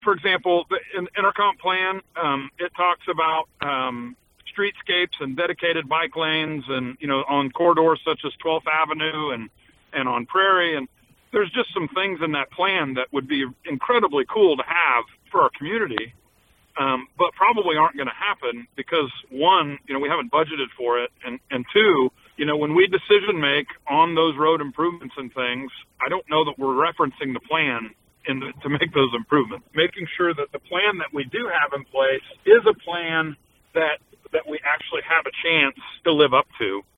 City commissioner discusses future following adoption of city zoning regulations last week